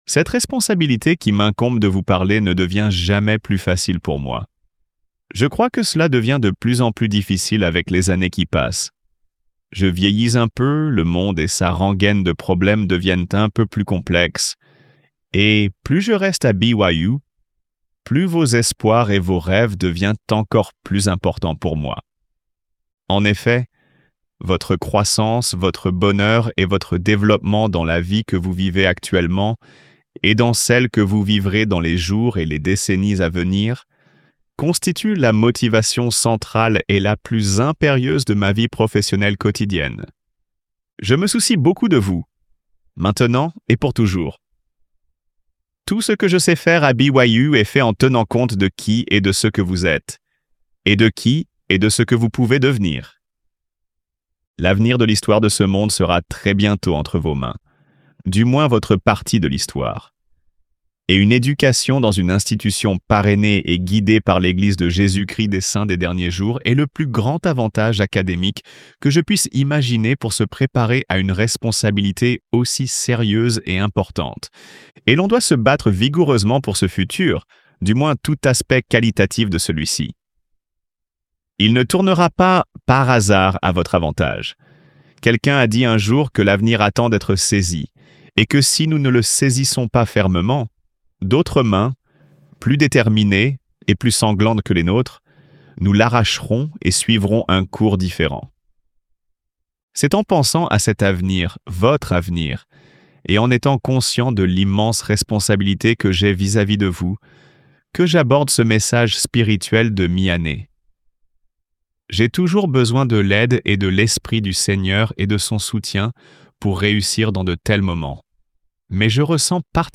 Jeffrey R. Holland parle de la chasteté et de l'intimité physique. Le pouvoir des âmes, des symboles et des sacrements est un don de Dieu.
Réunion spirituelle